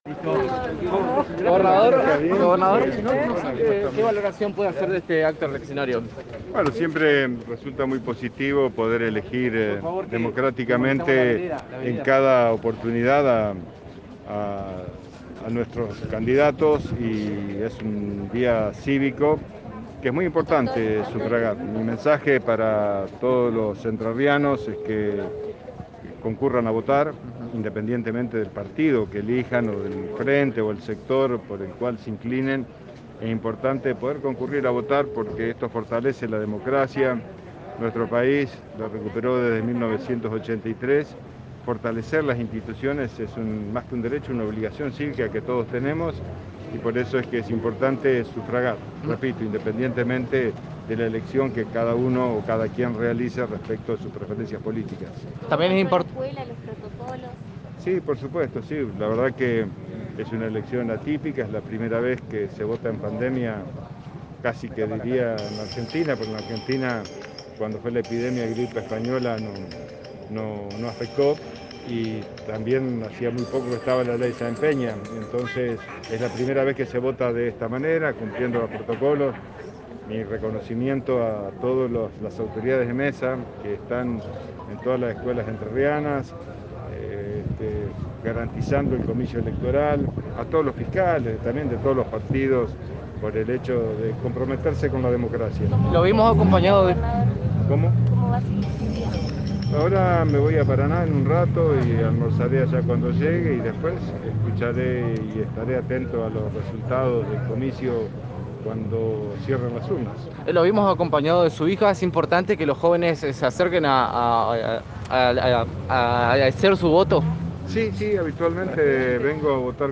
Luego del voto, dedicó unas palabras a la prensa y aseguró que la elección se estaba llevando a cabo de forma ordenada y sin grandes inconvenientes.